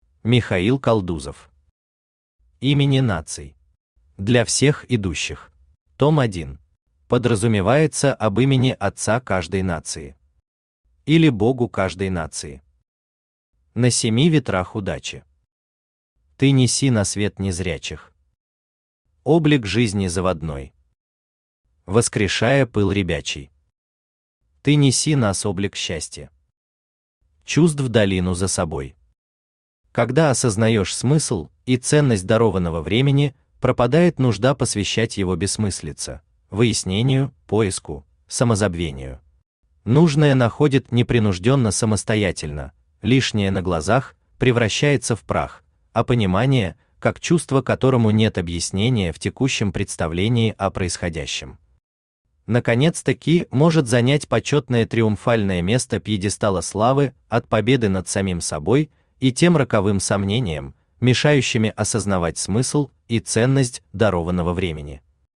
Для всех идущих Автор Михаил Константинович Калдузов Читает аудиокнигу Авточтец ЛитРес.